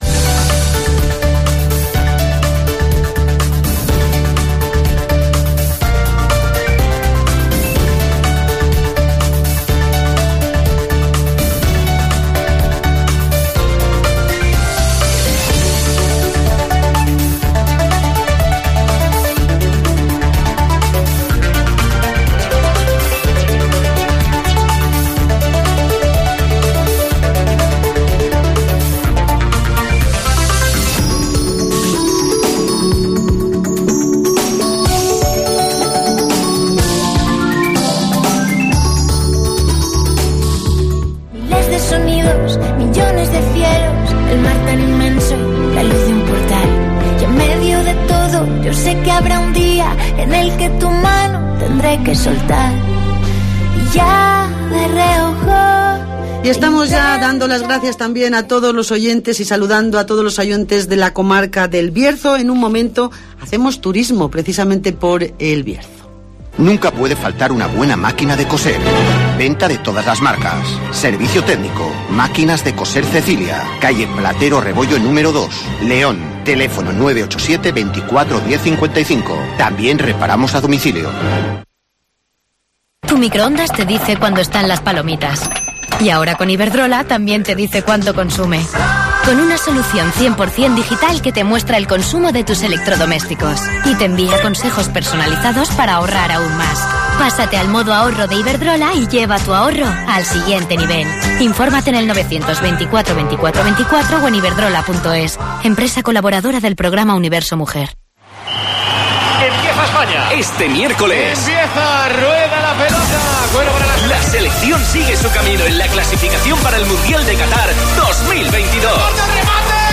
Las casas rurales del Bierzo, una opción segura para esta Semana Santa 2021 (Entrevista